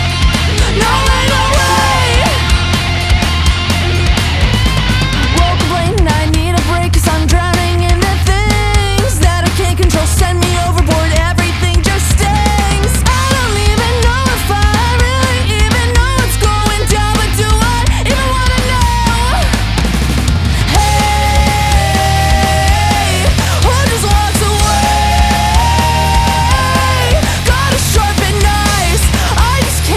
Жанр: Поп / Альтернатива / Панк